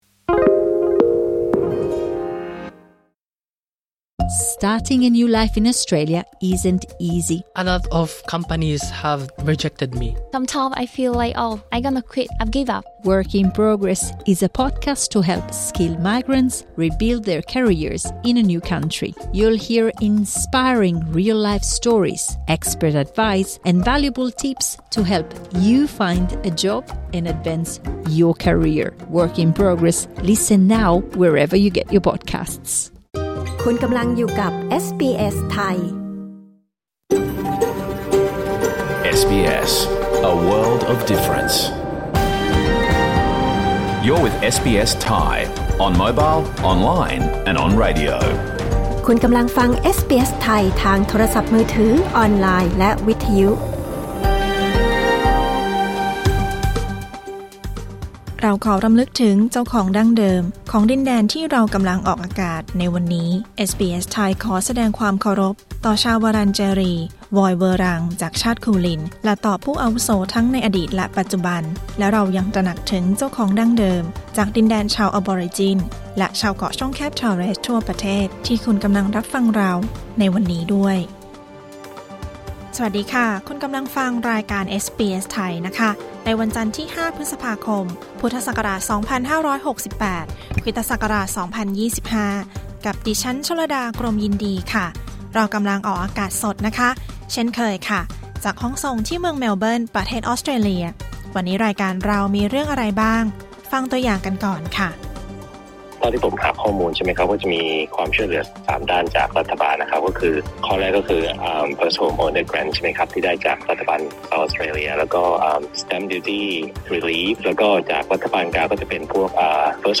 รายการสด 5 พฤษภาคม 2568